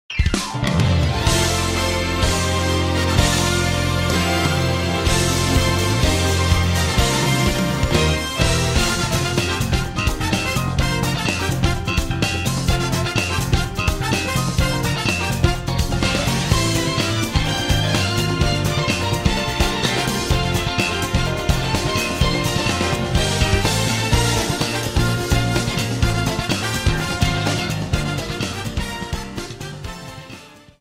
Credits music